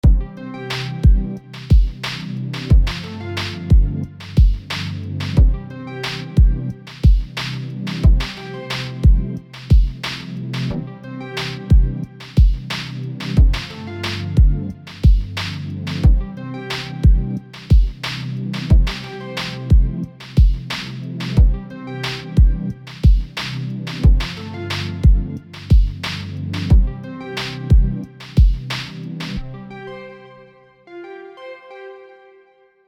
A small loop i made..
Game Music